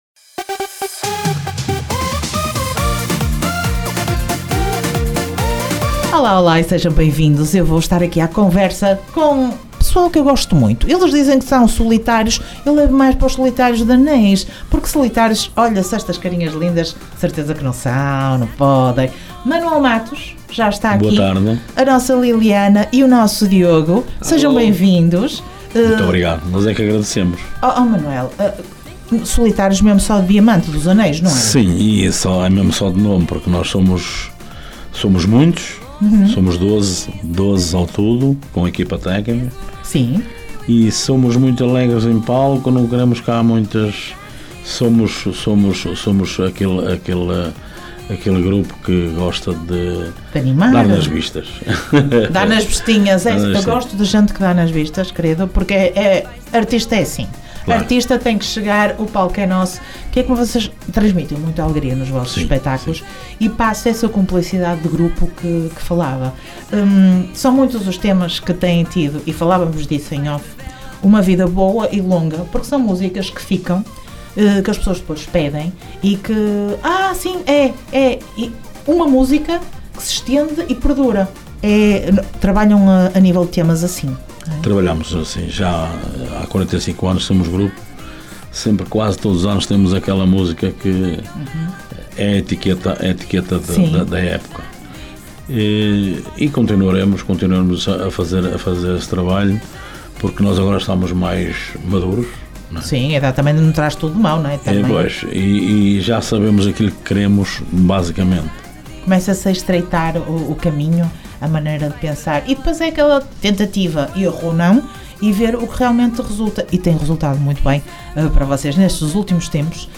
Entrevista Os Solitários dia 24 de Outubro.
ENTREVISTA-OS-SOLITARIOS.mp3